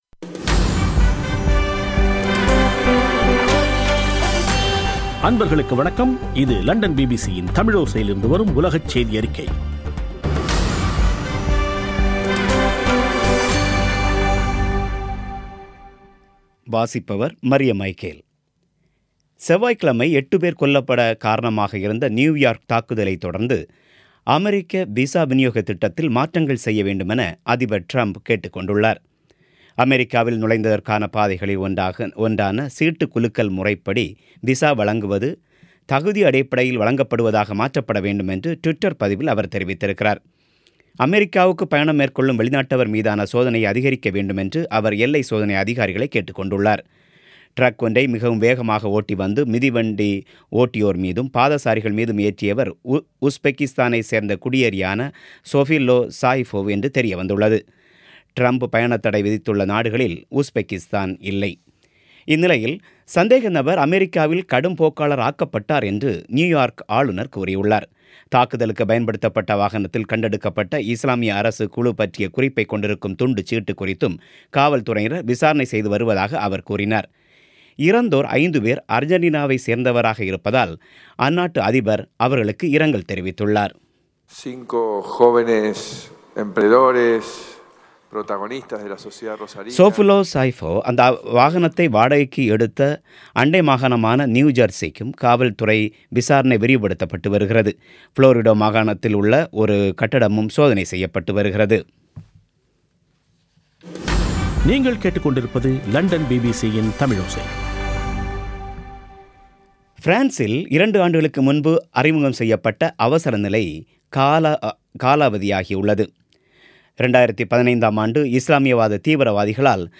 பிபிசி தமிழோசை செய்தியறிக்கை (01/10/2017)